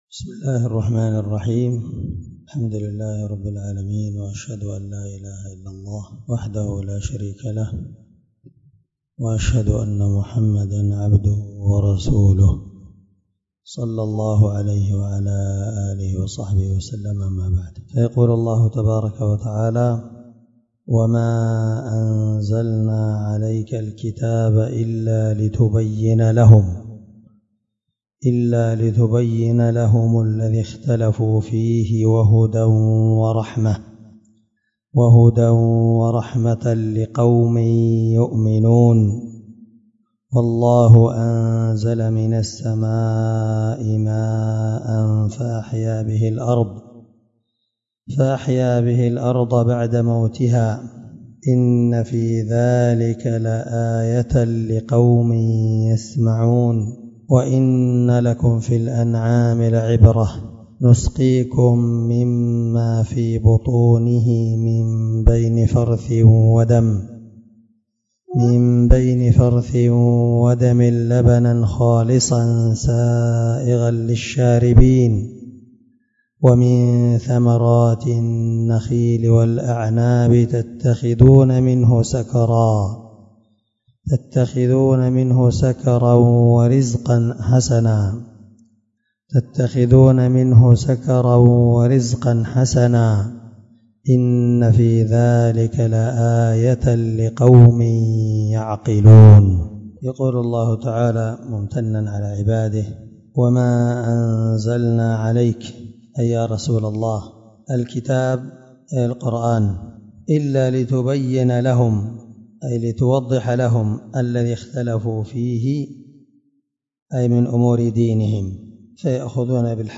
الدرس19 تفسير آية (64-67) من سورة النحل